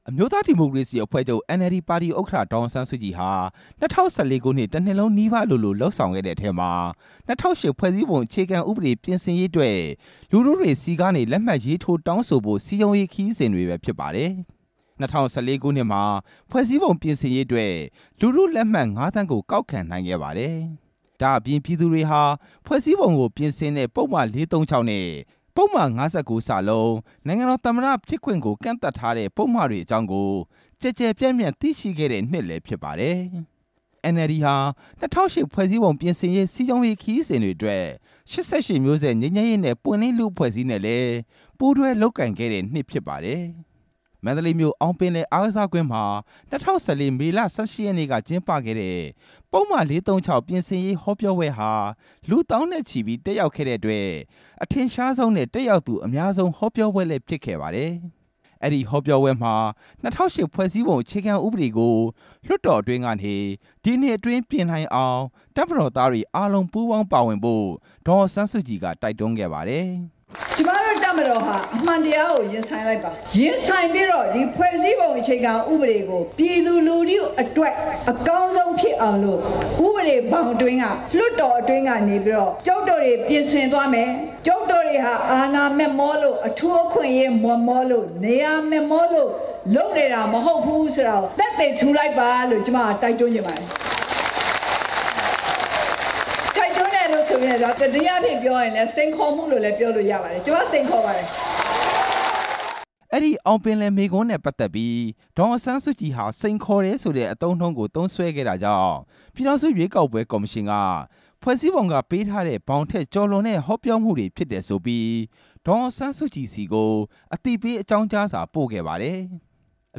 ဒေါ်အောင်ဆန်းစုကြည်ပြောကြားခဲ့တဲ့ မိန့်ခွန်းတွေထဲက အချက် တချို့